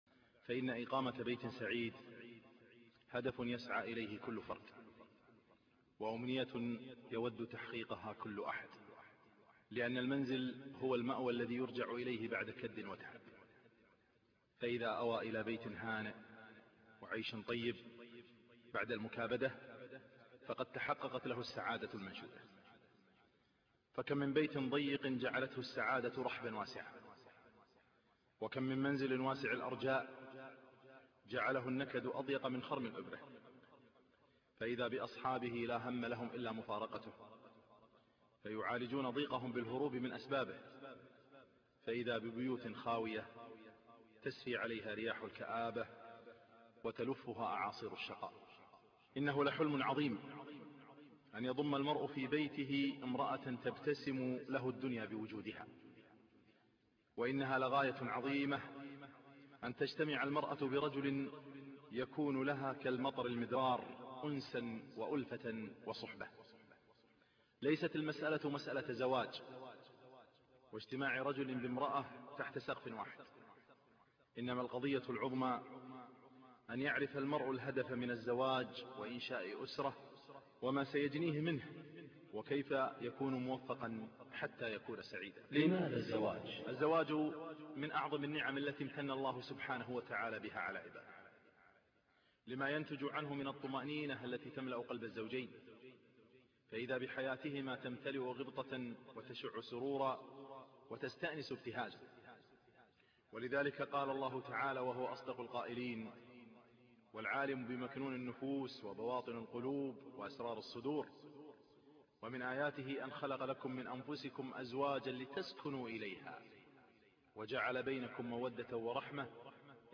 محاضرة - و ليسعك بيتك